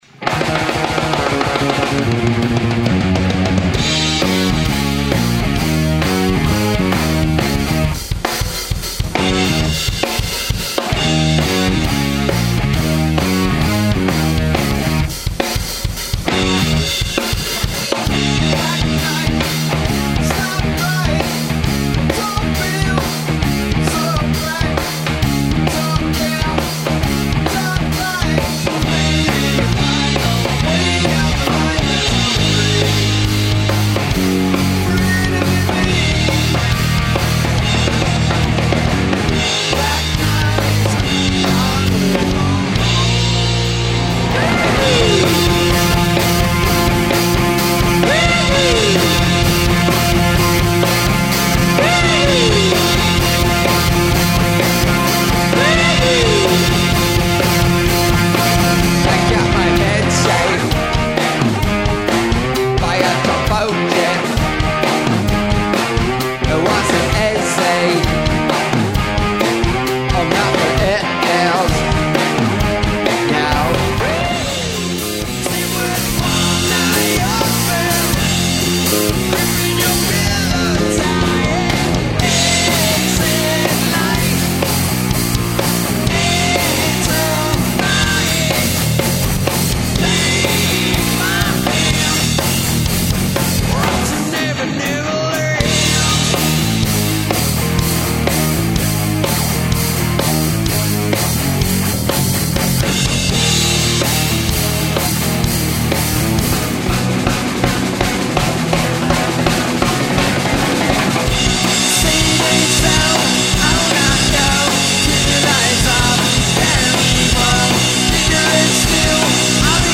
playing classic rock with high energy and professionalism
lead guitar and lead vocals
drums and lead and back up vocals
bass and back up vocals